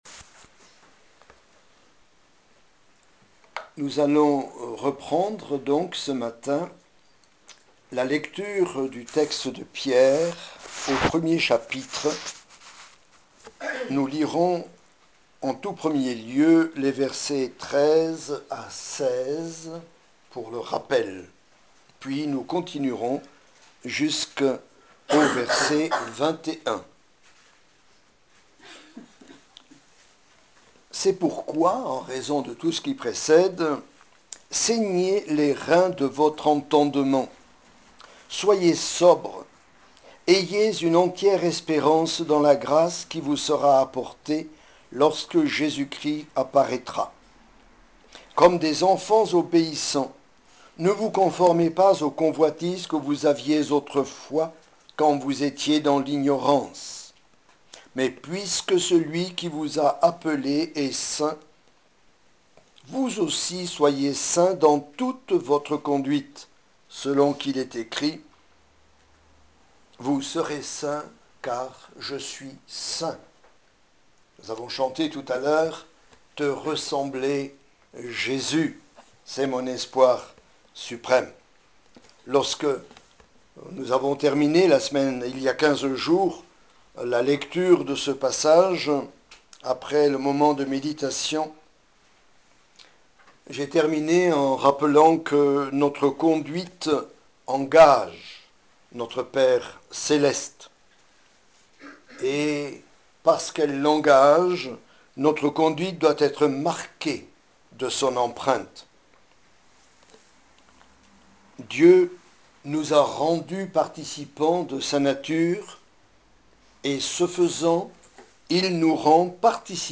Message enregistré à l’Eglise du Creusot le 14 novembre 2010